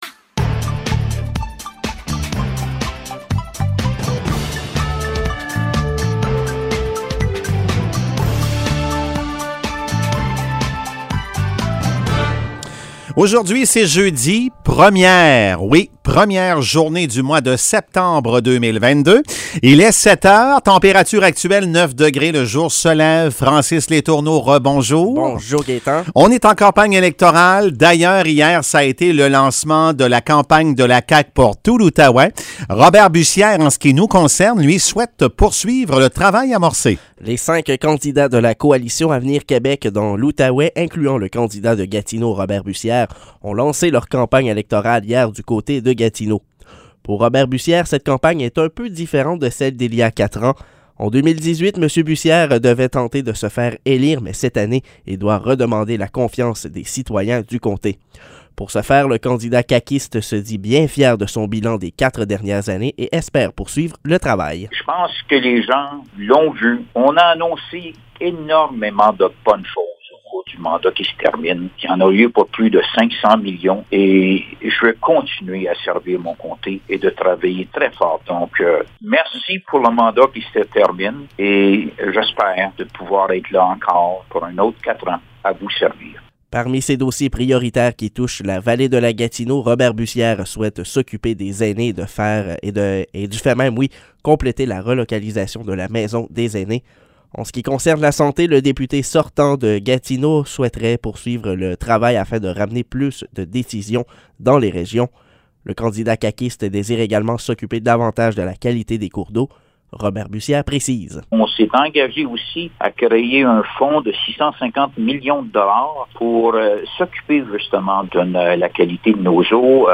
Nouvelles locales - 1er septembre 2022 - 7 h